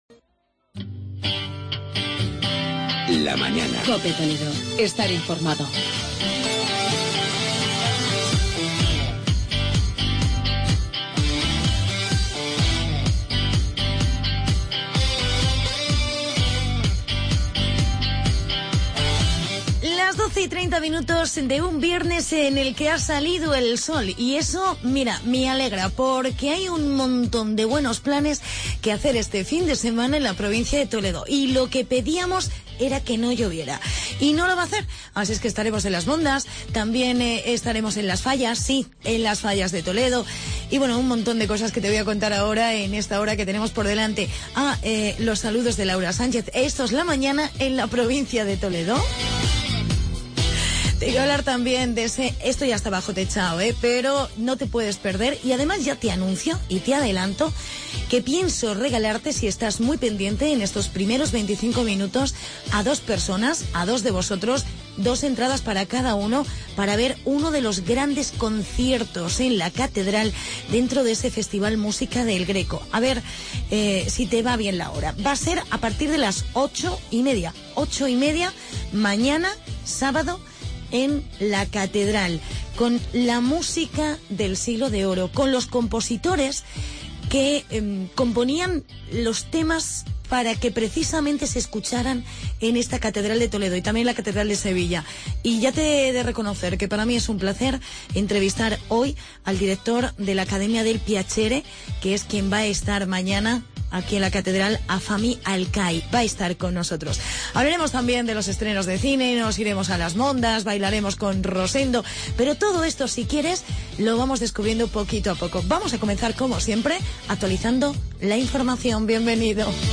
Entrevista al director de Accademia del Piacere, Fahmi Alqhai